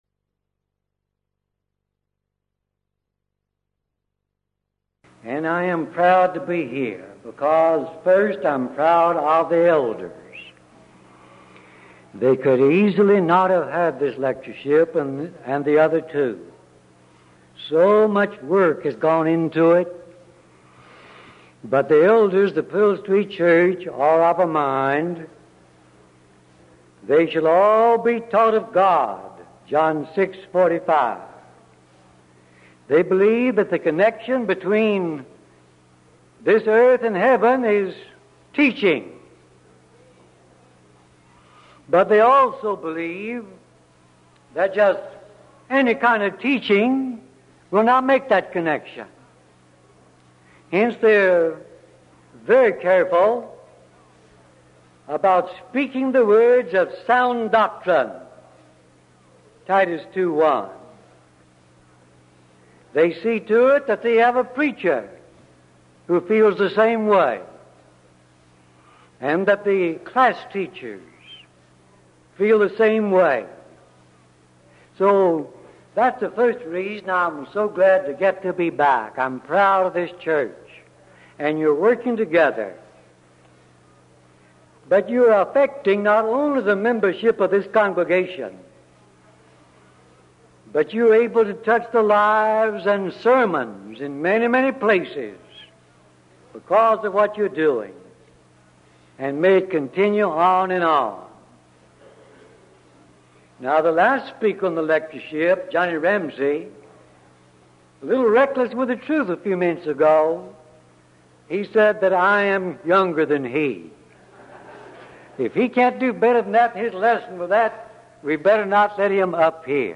Event: 1984 Denton Lectures Theme/Title: Studies in the Book of Revelation